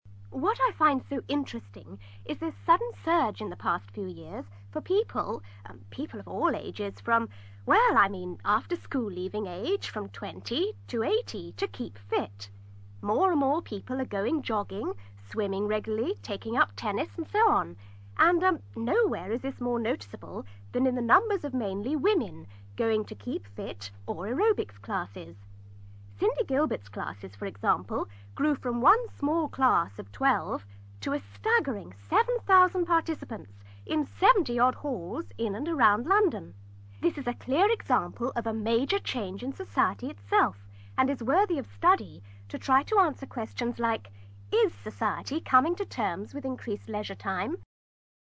ACTIVITY 185: You are going to hear people from different professions talking about the boom in the 'Keep-Fit' industry.